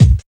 108 KICK.wav